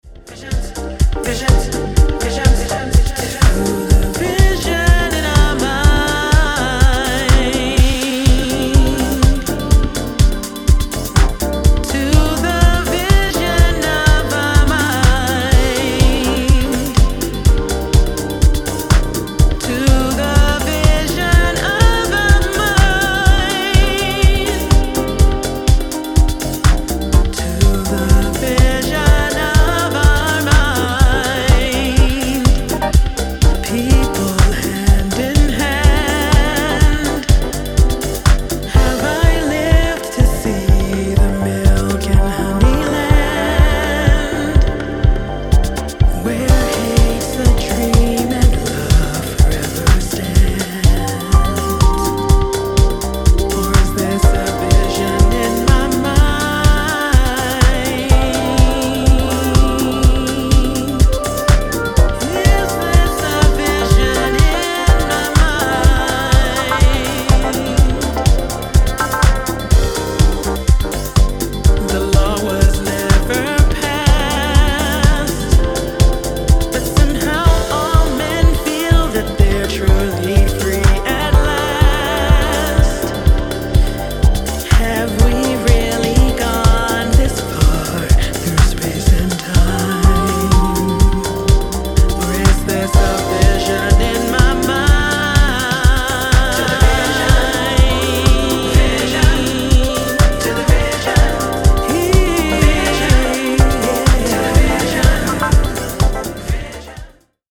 a lively bassline, thoughtful keys and layered vocals